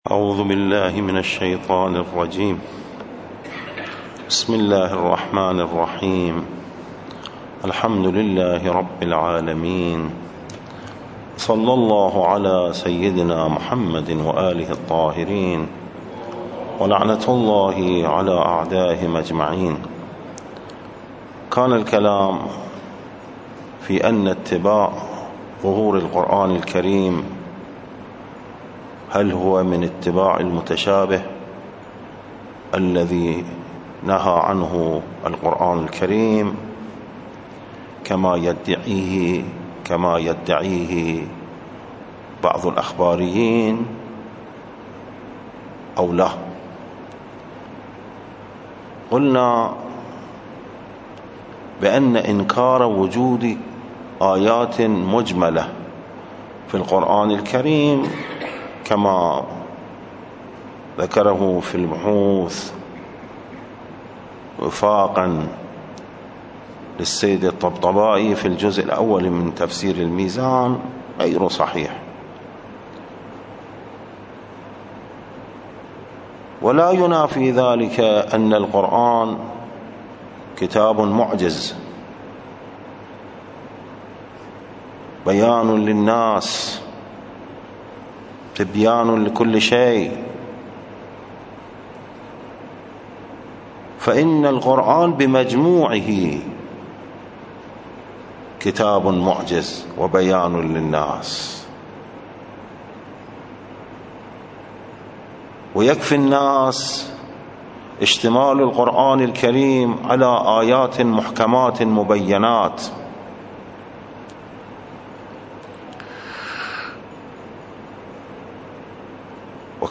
الدرس: 83